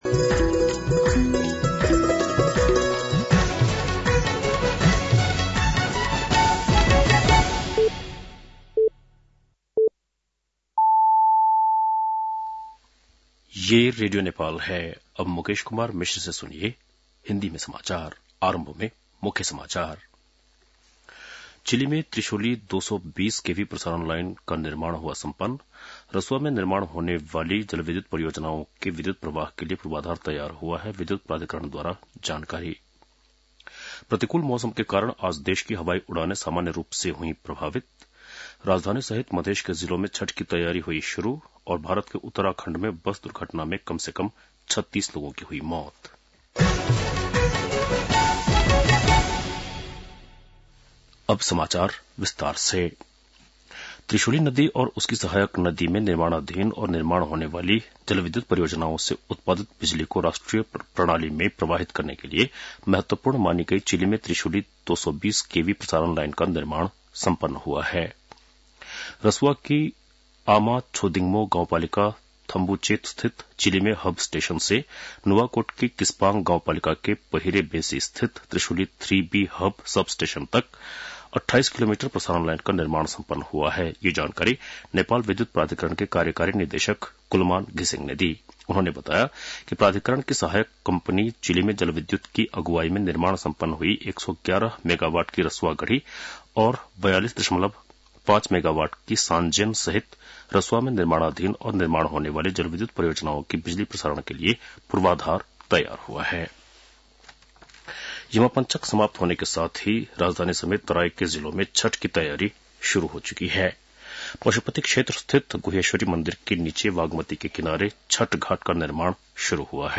बेलुकी १० बजेको हिन्दी समाचार : २० कार्तिक , २०८१